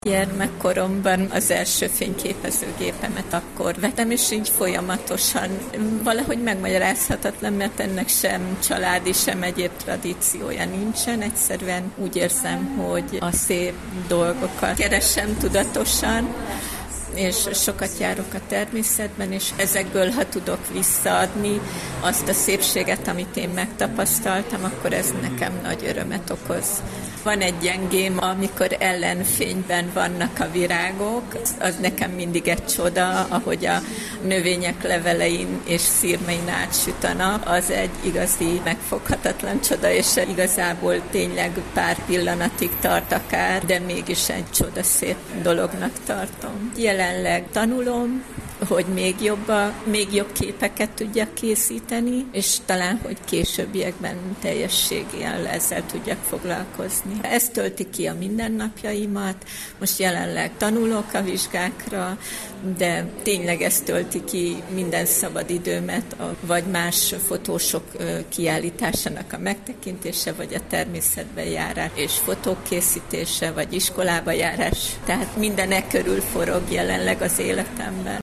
kiallitas_megnyito.mp3